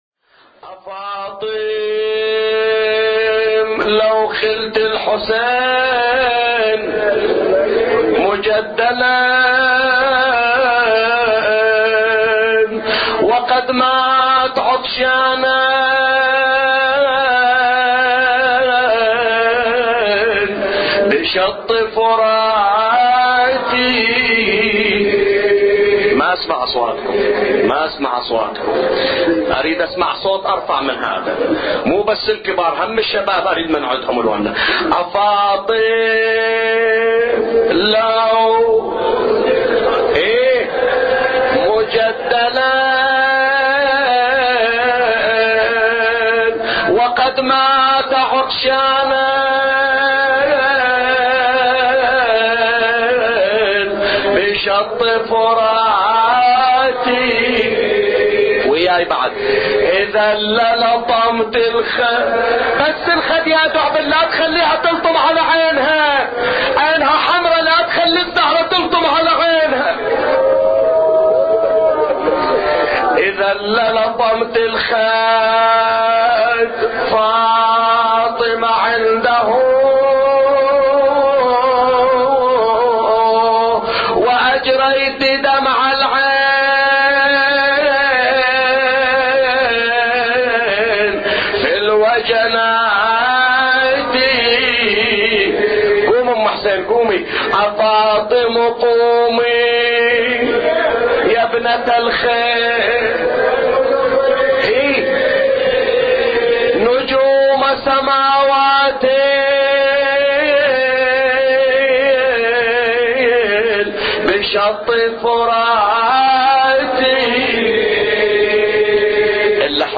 أبيات حسينية – ليلة الحادي من شهر محرم